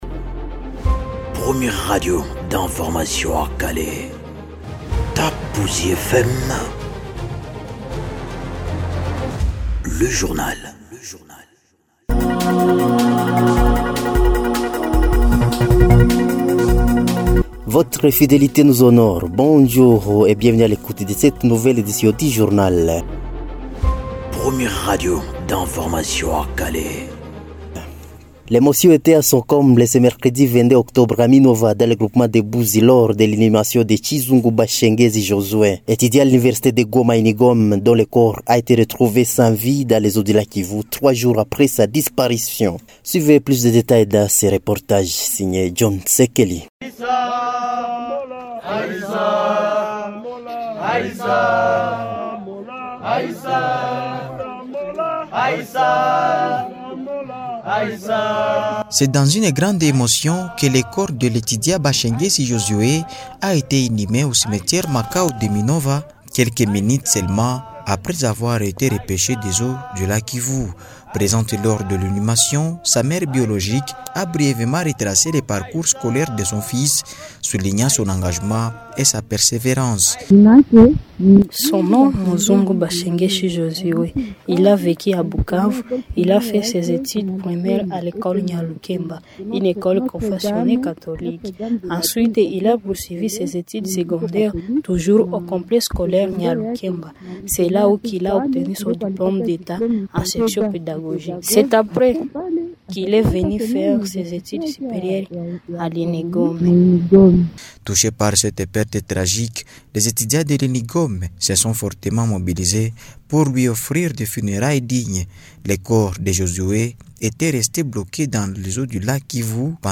Journal soir mercredi 22 octobre 2025
Bonsoir à toutes et à tous et bienvenue à l’écoute du journal parlé de Top Buzi FM, ce mercredi 22 octobre 2025.